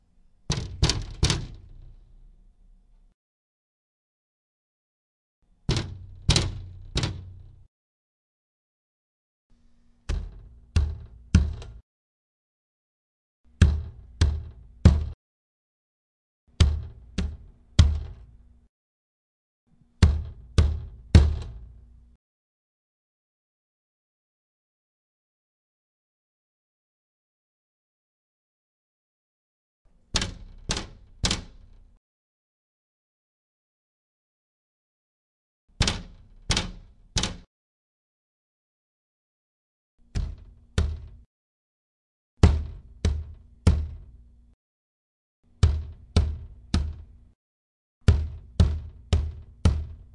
墨西哥城" 打孔门
描述：我打了一个由玻璃和木头制成的门
标签： 玻璃 冲压
声道立体声